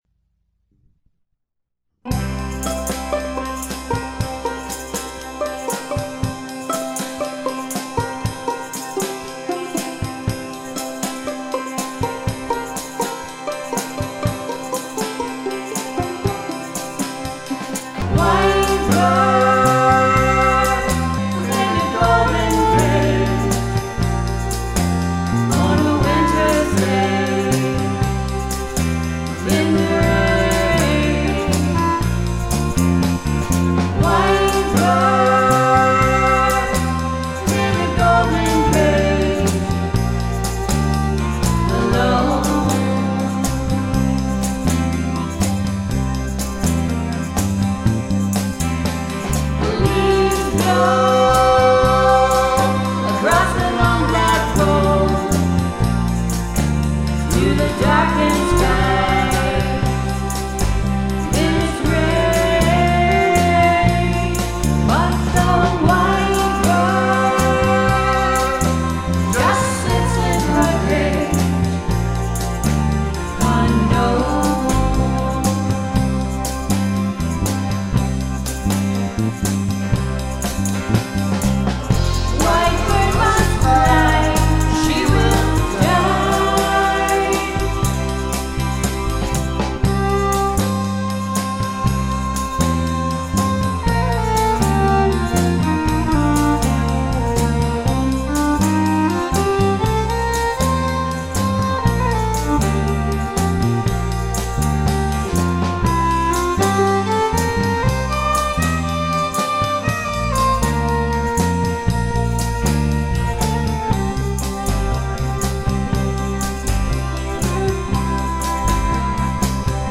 first studio album
vocal harmonies
violin